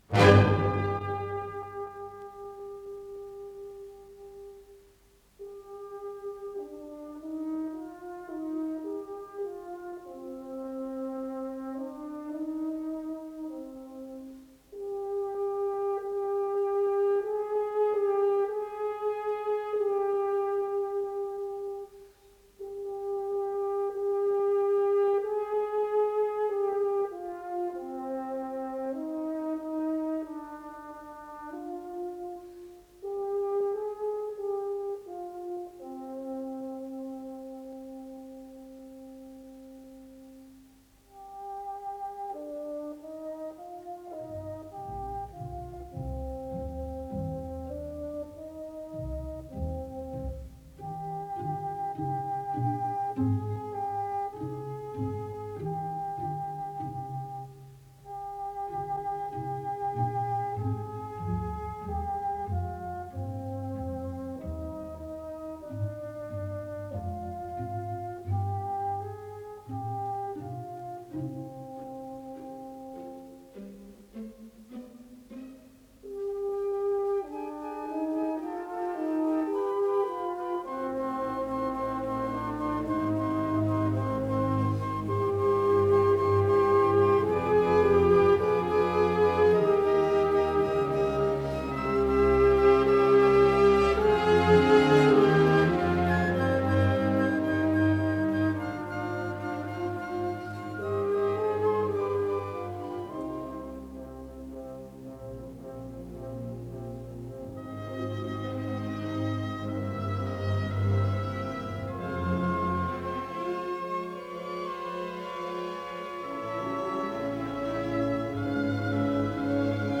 П-0210 — Вторая симфония — Ретро-архив Аудио
Исполнитель: Государственный симфонический оркестр СССР
До минор